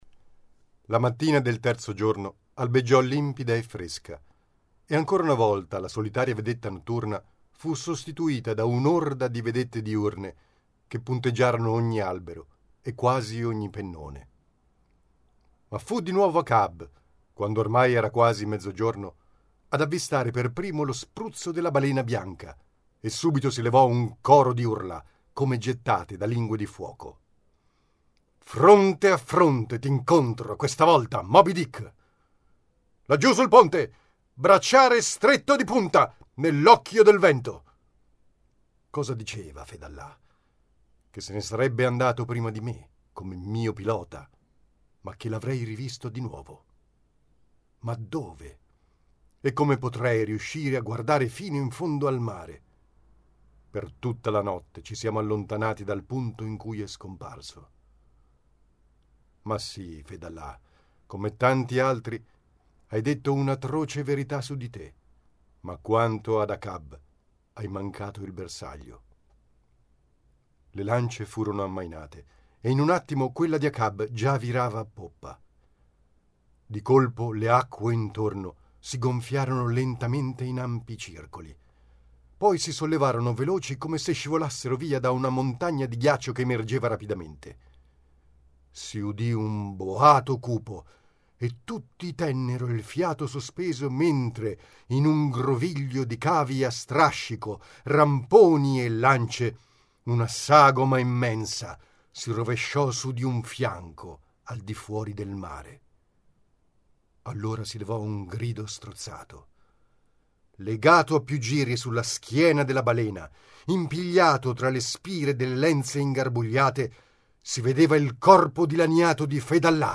Recitazione
Ecco una versione audio di prova, registrata il giorno prima: